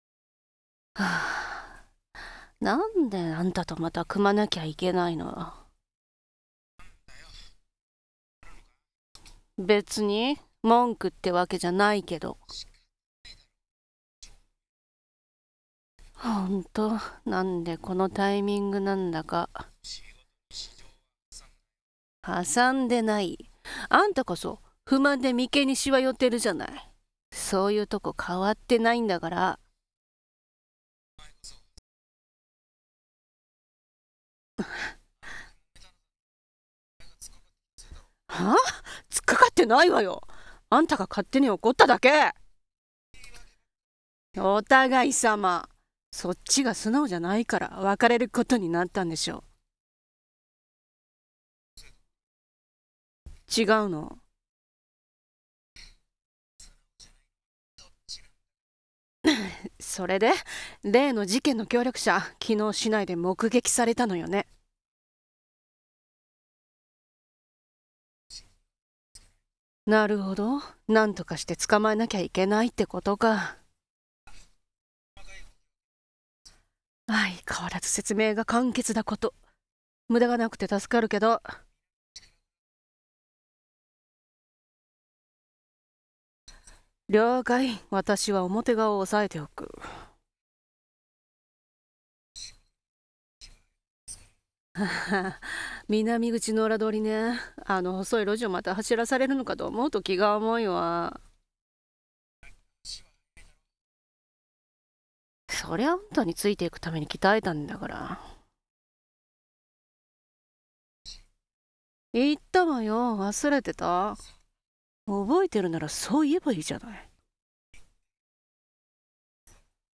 【二人声劇】結局、相棒なわけで。